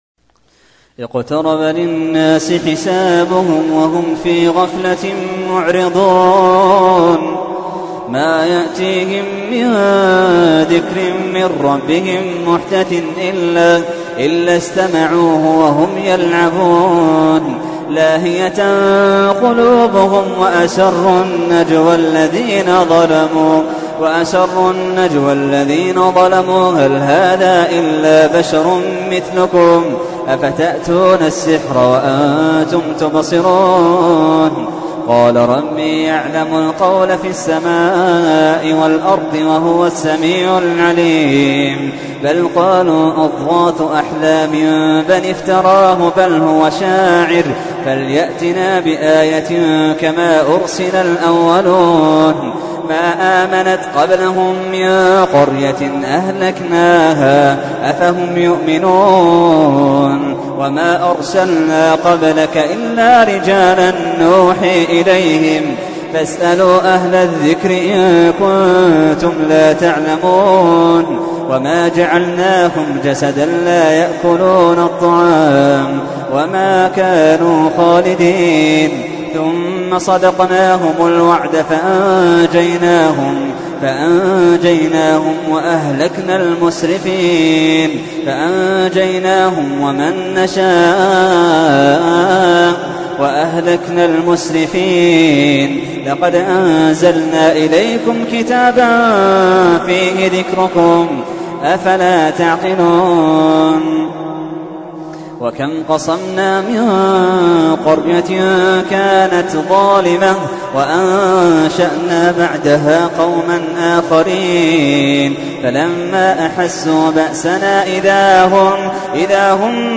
تحميل : 21. سورة الأنبياء / القارئ محمد اللحيدان / القرآن الكريم / موقع يا حسين